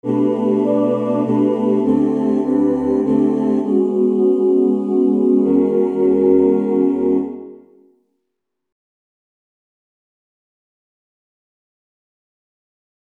Key written in: E Major
How many parts: 6
Comments: Soothing and easy-going does it, don't push!
All Parts mix: